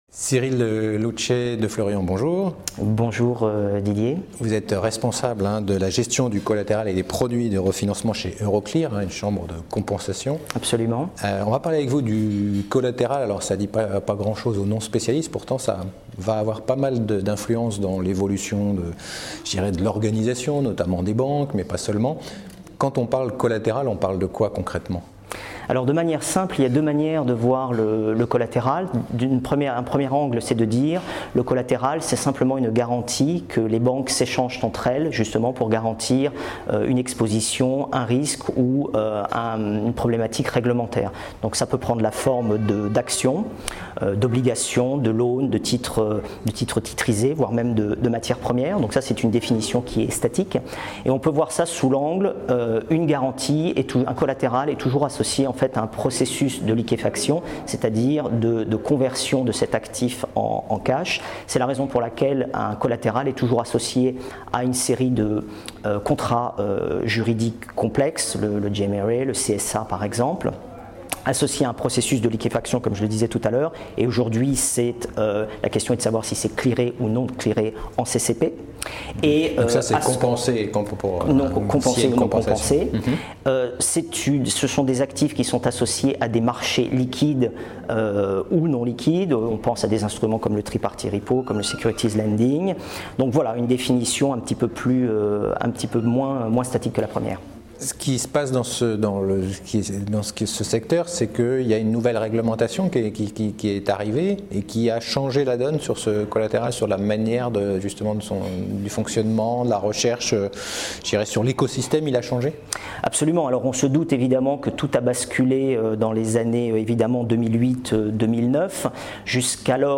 Rencontres 2014 de Paris Europlace.
A cette occasion la Web Tv a interviewé des personnalités qui intervenaient lors de tables-rondes.